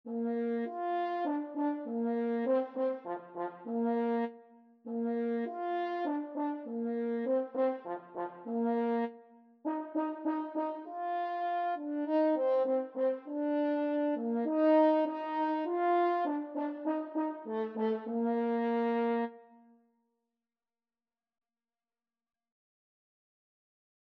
French Horn version
Free Sheet music for French Horn
Bb major (Sounding Pitch) F major (French Horn in F) (View more Bb major Music for French Horn )
4/4 (View more 4/4 Music)
Moderato
F4-F5